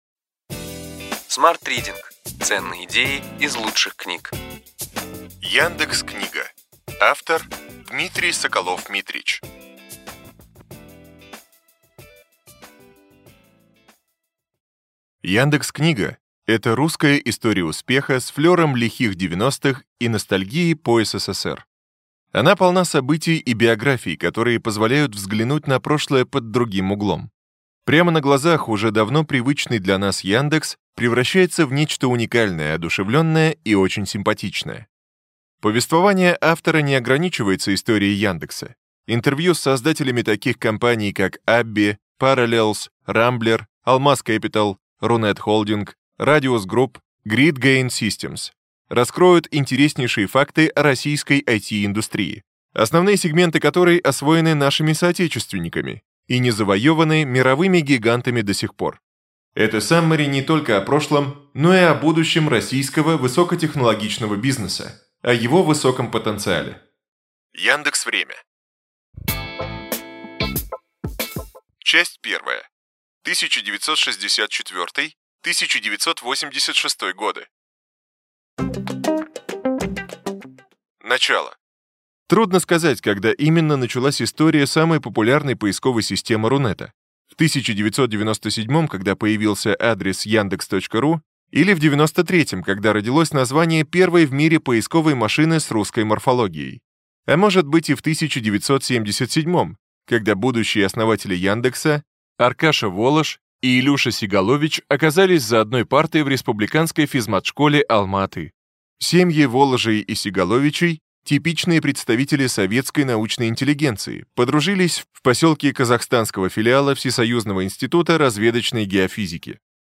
Аудиокнига Ключевые идеи книги: Яндекс.Книга